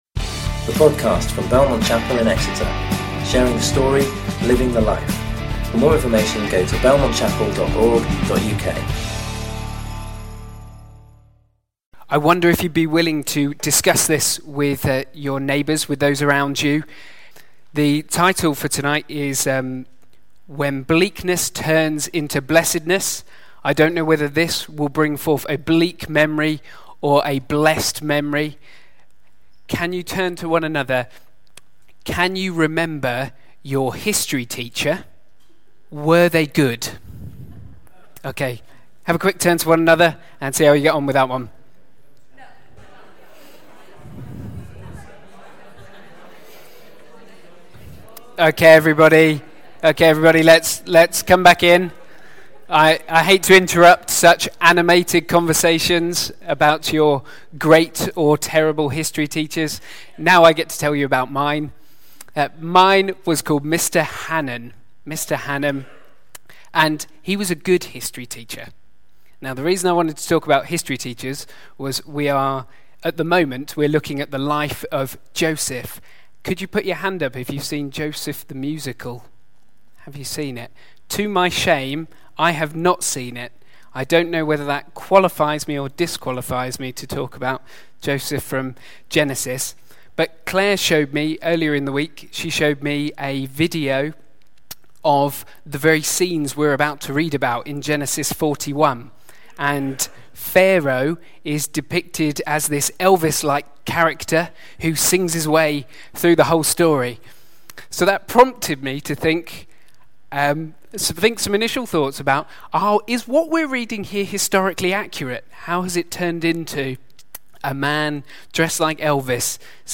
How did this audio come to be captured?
Conference day held at Belmont Chapel on 30th Sept 2017